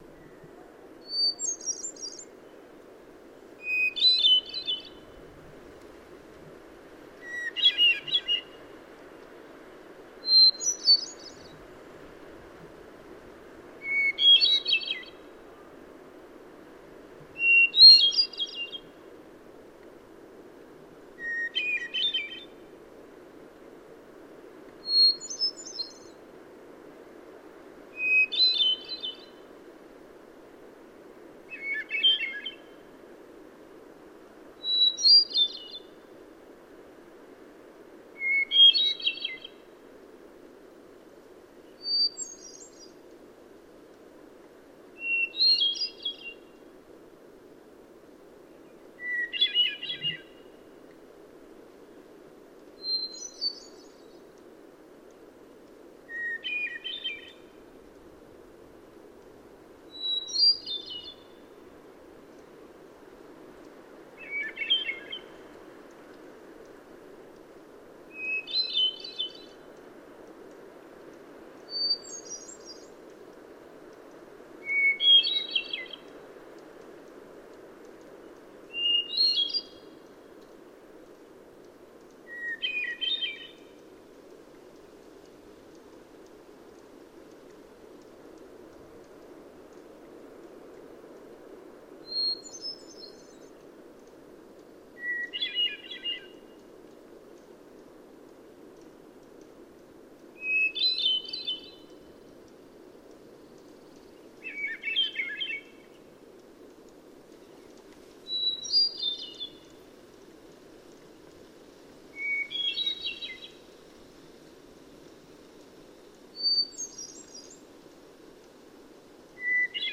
Hermit Thrush
Recorded in the Rockies by